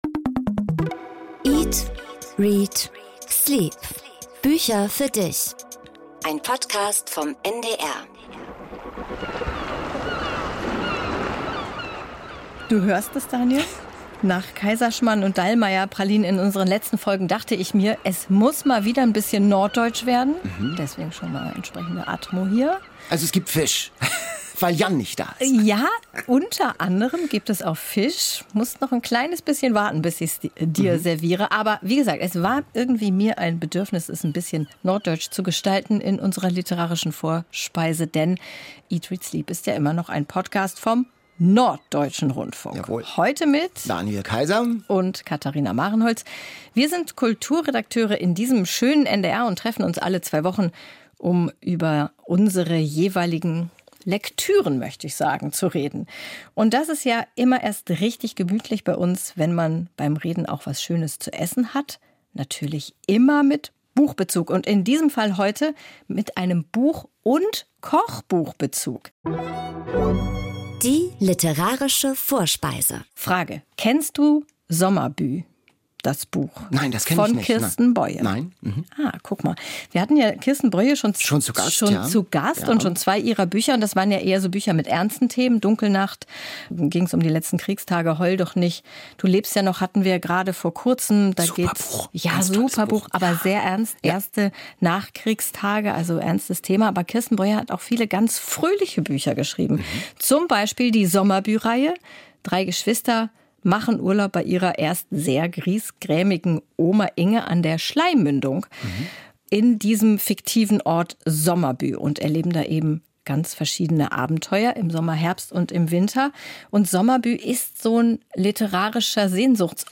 Aber es geht auch nach Berlin (in der Bestseller-Challenge), nach Cape Cod (mit dem Roman "Papierpalast", der beide Hosts richtig gut gefallen hat), in die Berge und nach Masuren (mit den All Time Favorites) und nach Schweden: Der Autor David Lagercrantz wird aus dem ARD Studio Stockholm zugeschaltet und erzählt über seinen neuen Krimi.
00:32:08 Interview mit David Lagercrantz